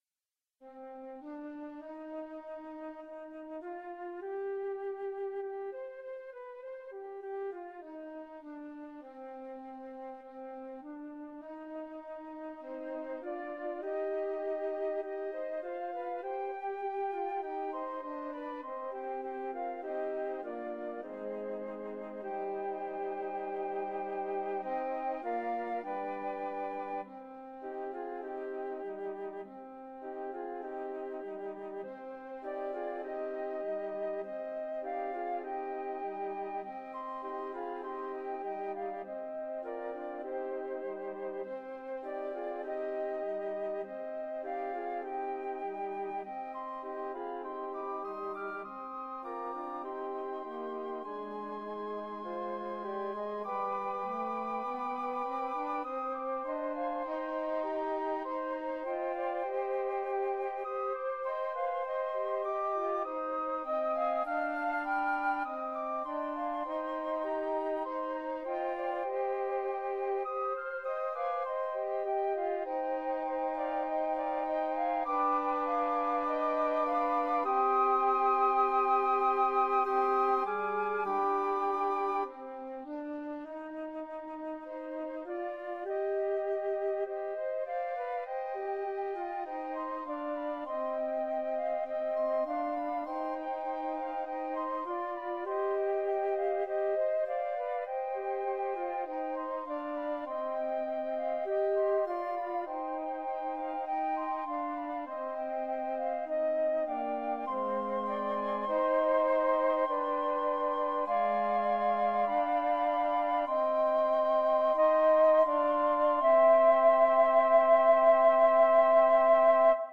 canonic folk song (round)
Folk and World